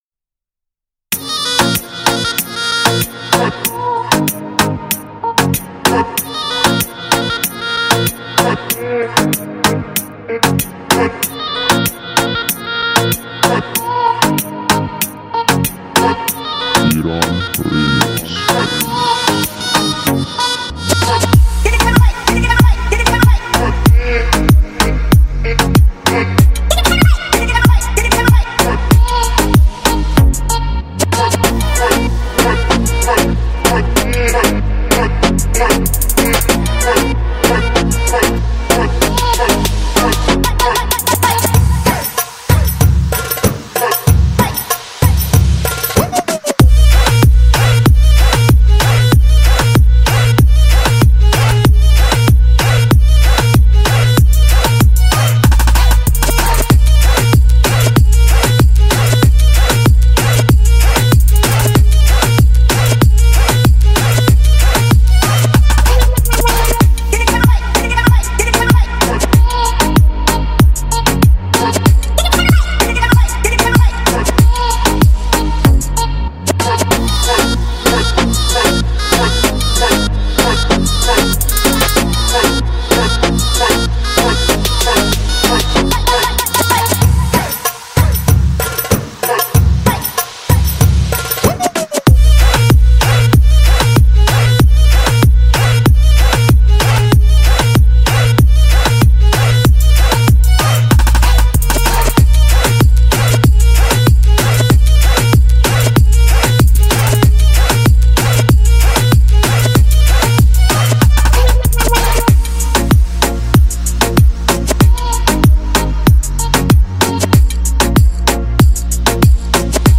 Download Arabic remix for the system